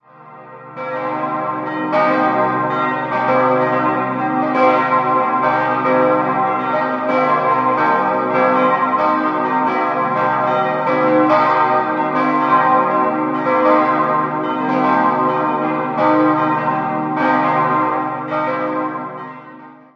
Beschreibung der Glocken
Jahrhunderts erfolgte die Barockisierung des Innenraumes. 5-stimmiges erweitertes C-Moll-Geläute: c'-es'-g'-b'-c'' Eine genaue Glockenbeschreibung folgt unten.